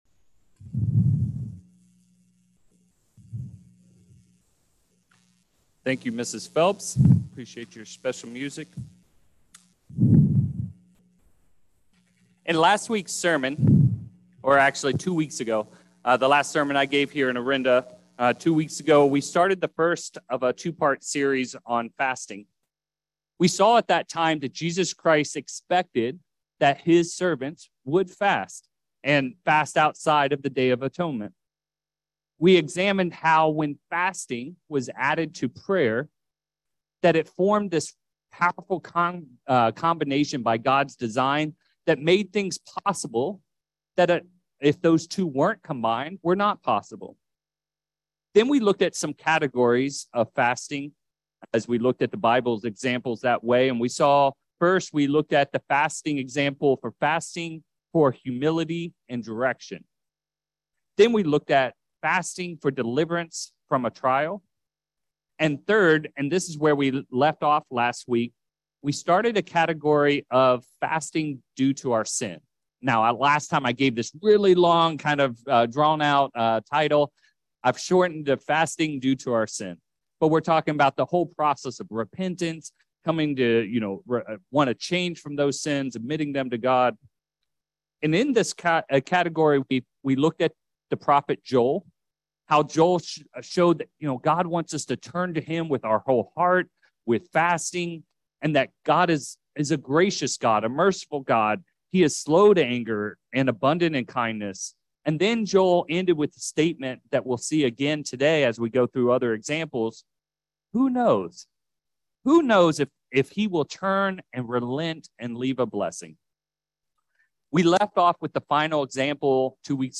Orinda In this second sermon of a two-part series on fasting, we pick up where part one left off. We will start with fasting due to our sins and then look at fasting when faced with temptations.